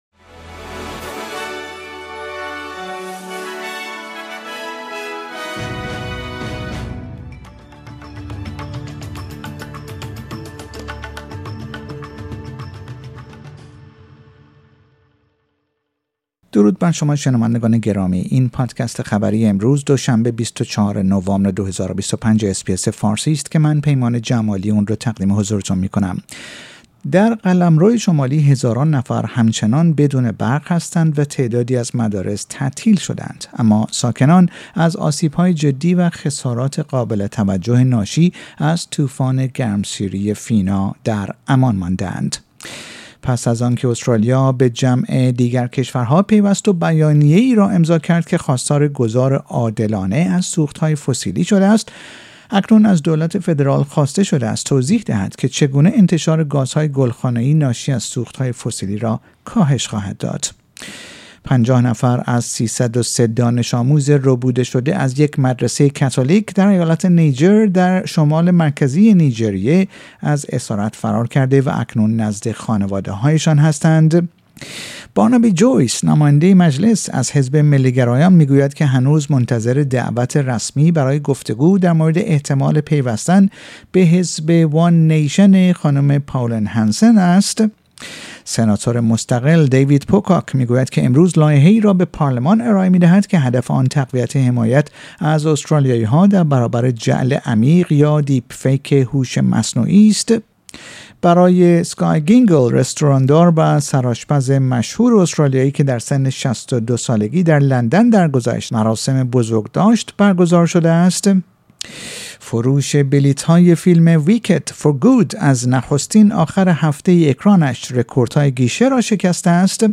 در این پادکست خبری مهمترین اخبار روز دوشنبه ۲۴ نوامبر ارائه شده است.